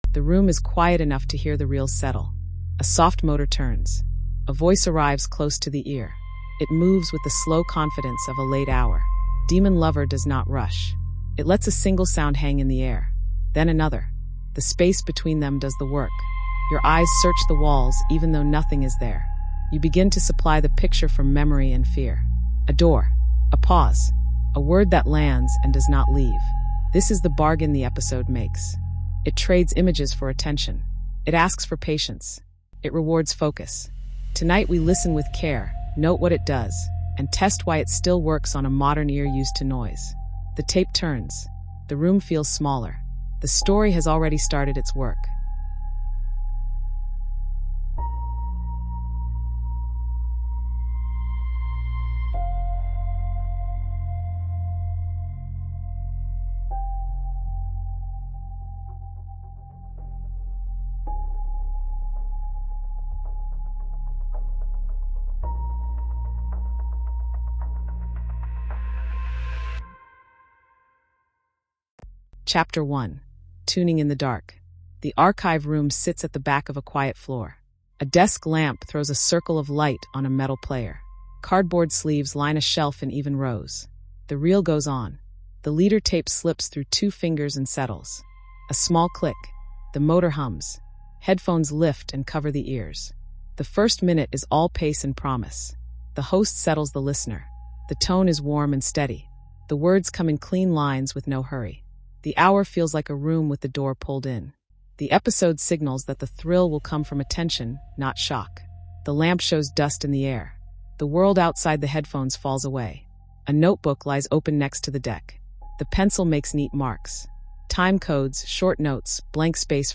A calm, scene-led investigation of how “Demon Lover” from CBS Radio Mystery Theater built fear with silence, close-mic voices, and exact foley. This cultural history documentary follows the nineteen-seventies radio revival, E. G. Marshall’s steady hosting, and the production discipline that turned a late-night hour into a lasting audio drama touchstone. We trace clock sense, room mapping, restraint in music, and ethical framing, then translate those lessons for today’s earbuds and podcasts.